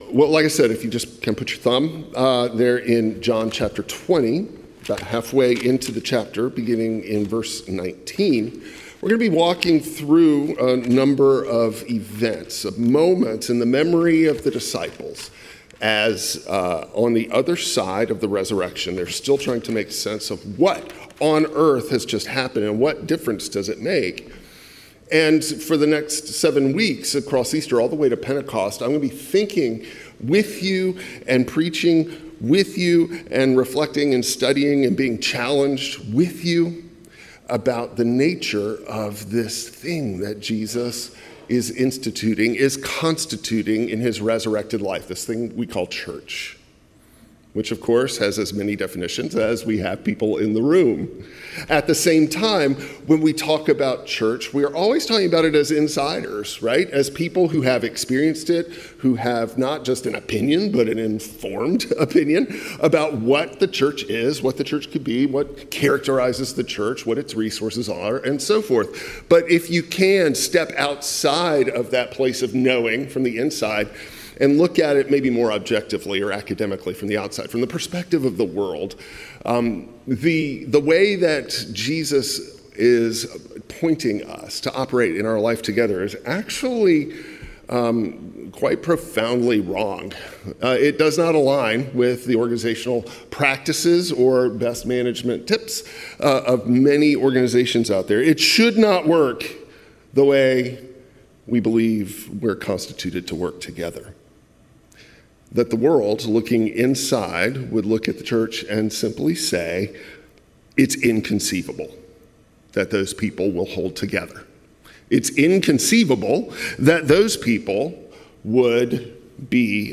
John 20:19-31 Service Type: Traditional Service The disciples locked the doors after Easter.